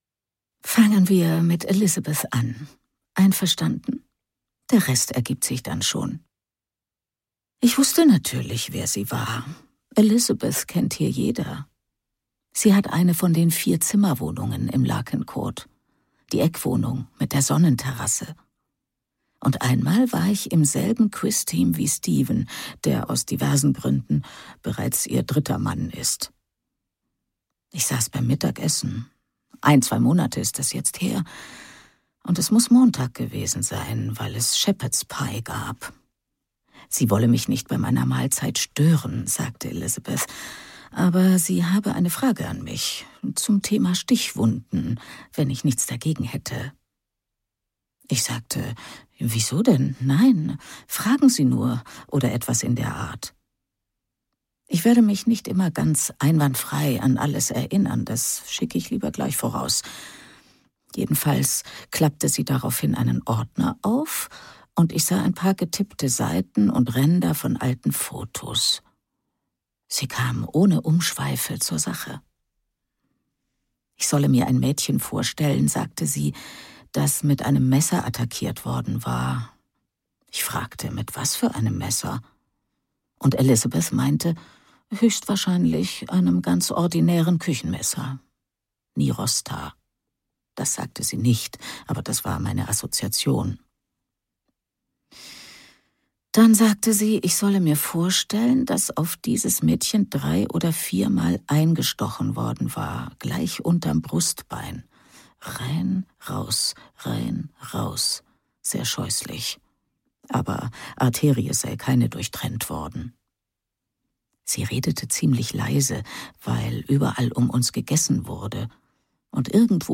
Der Donnerstagsmordclub (DE) audiokniha
Ukázka z knihy